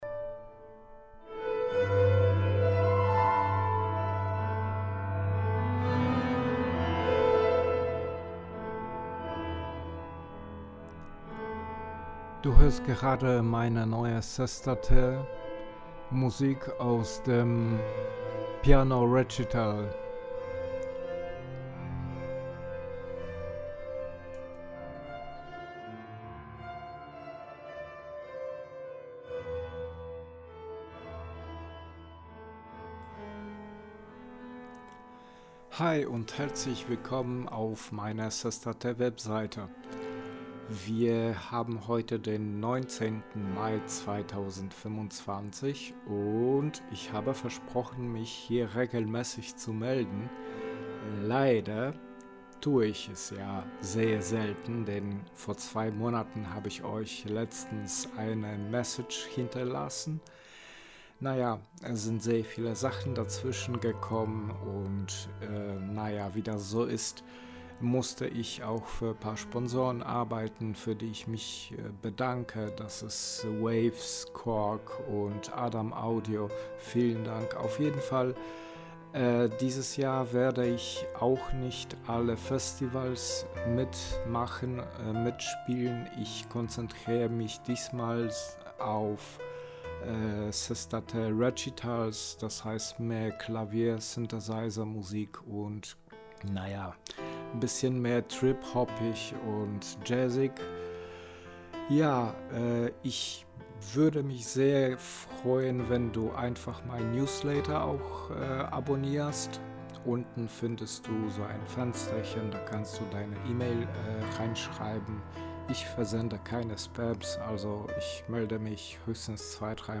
Demo-Vorschau für Fans.